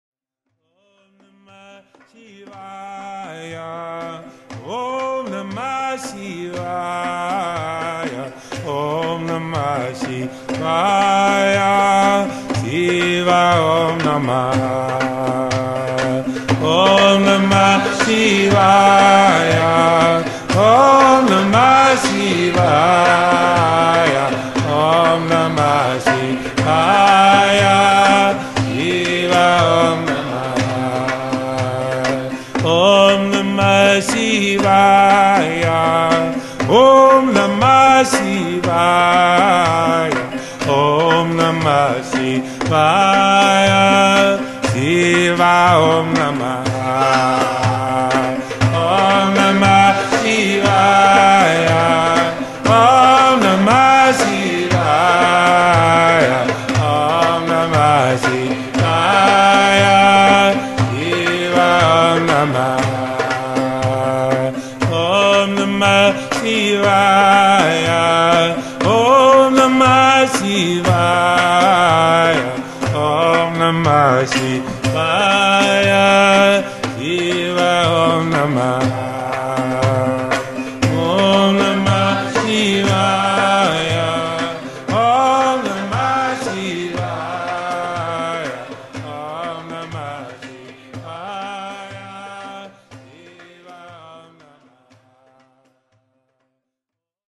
Live Kirtan Chanting CD
This is a live recording of one these Kirtans.